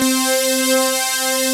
LYRLEAD4.wav